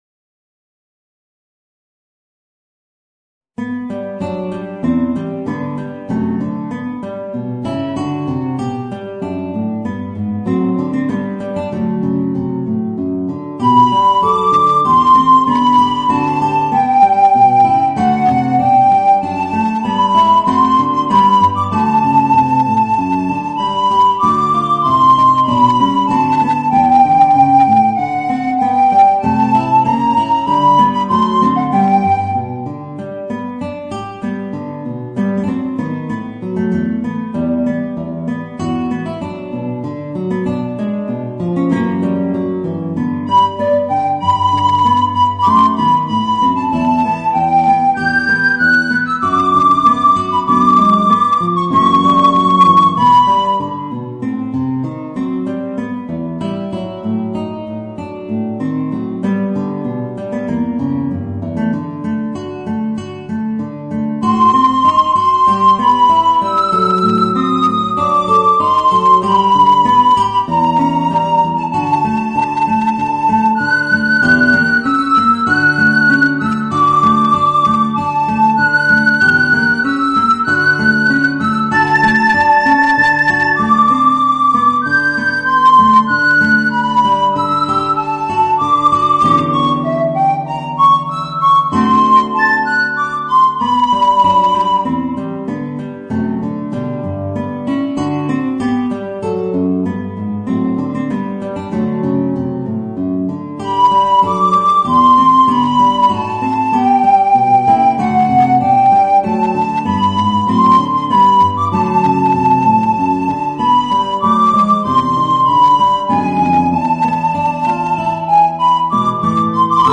Voicing: Guitar and Soprano Recorder